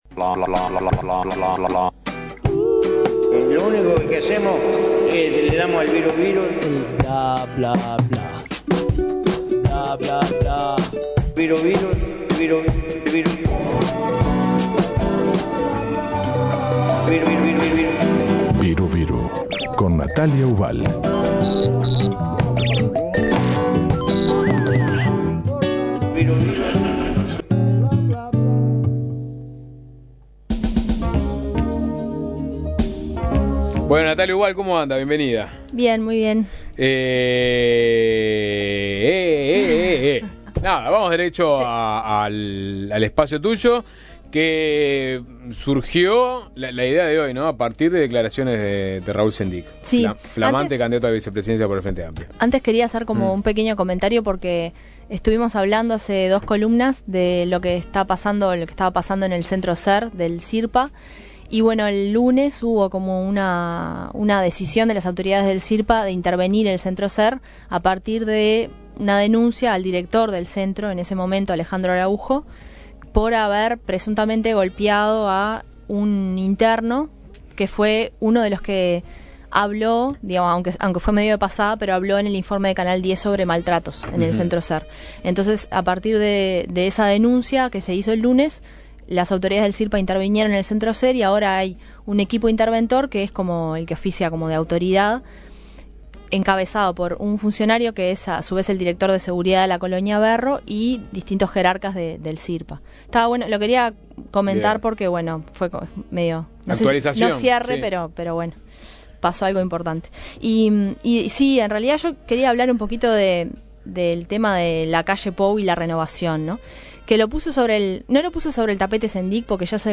En la columna escuchamos a Pablo Da Silveira, posible Ministro en un eventual gobierno de Lacalle Pou. Da Silveira respondió a los dichos de Raúl Sendic, candidato a Vice por el Frente Amplio, sobre este tema.